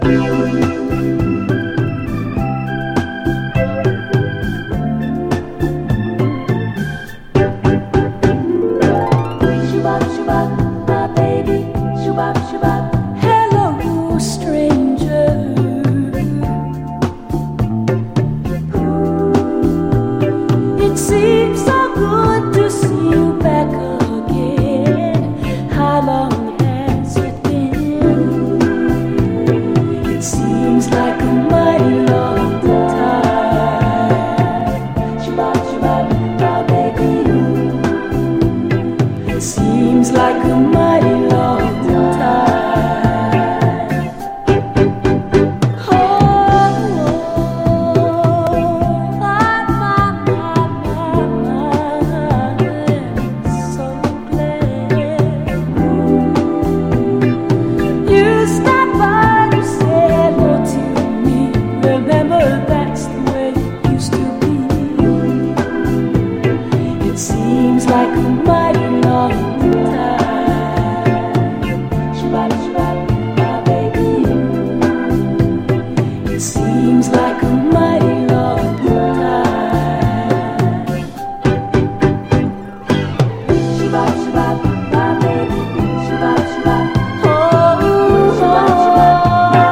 SOUL / SOUL / 70'S～ / DISCO
B級ディスコ・カヴァー！